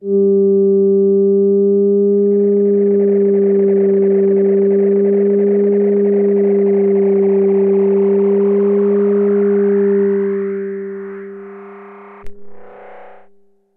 标签： midivelocity32 FSharp4 midinote67 ElektronAnalogFour synthesizer singlenote multisample
声道立体声